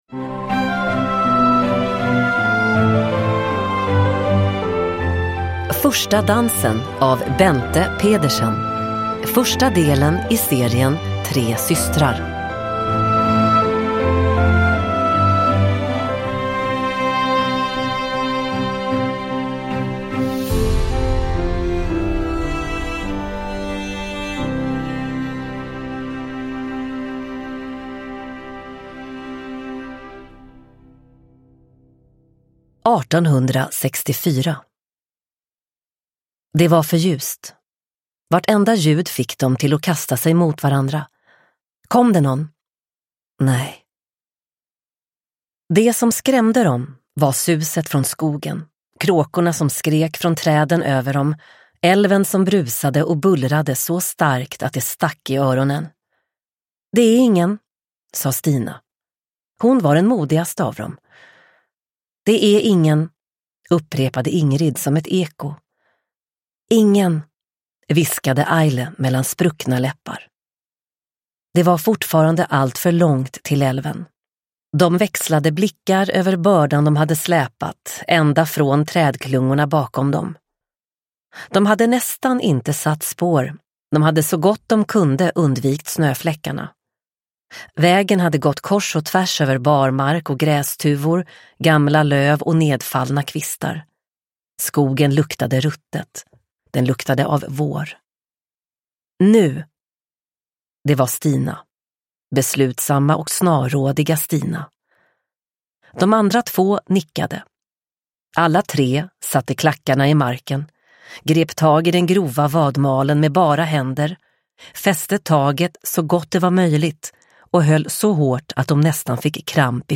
Första dansen – Ljudbok – Laddas ner